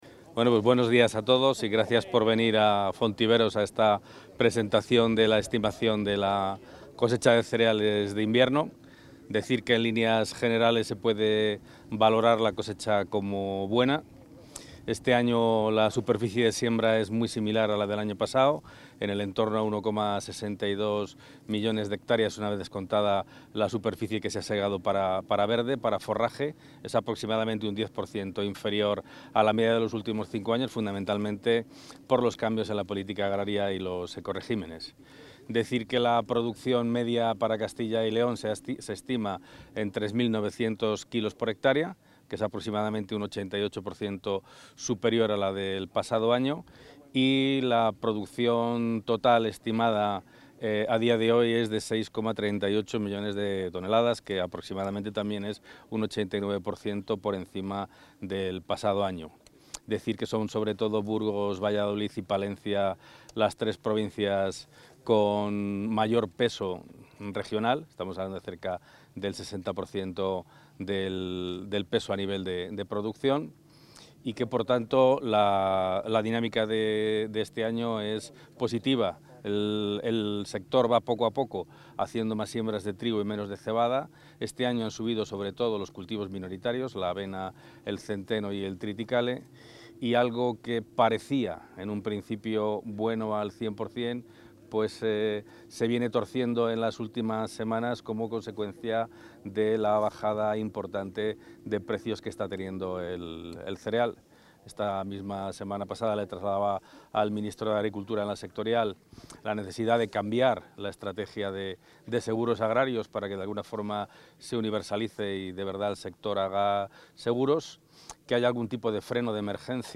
Intervención del consejero.
El consejero de Agricultura, Ganadería y Desarrollo Rural, Gerardo Dueñas, ha participado este lunes en el acto de presentación de la cosecha de cereal de invierno de este 2024, que se ha celebrado en Fontiveros (Ávila).